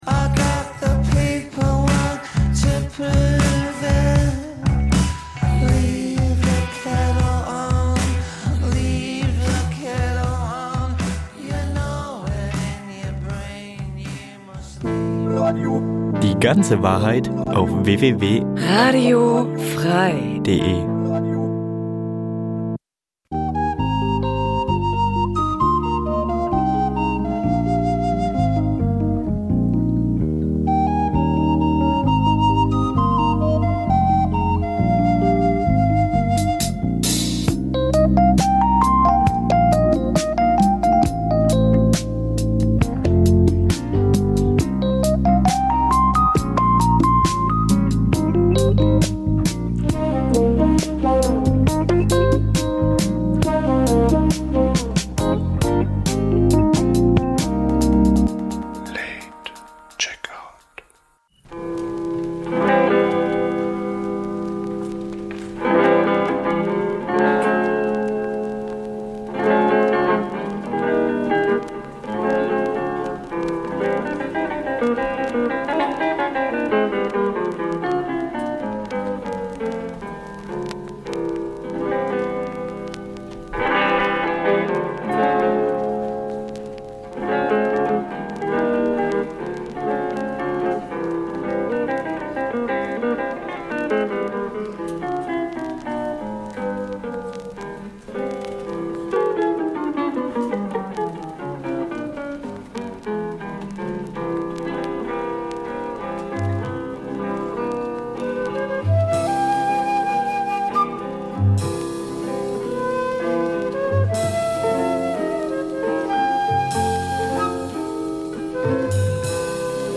In der Sendung werden neben Neuerscheinungen aus der weiten Welt der Housemusik vor allem die Tracks jener Musikrichtung vorgestellt, die in den vergangenen Jahren nur kaum oder wenig Beachtung fanden. Au�erdem pr�sentieren wir dem H�rer unsere aktuellen Ausgeh-Tipps f�r das Wochenende und einen kurzen DJ-Mix.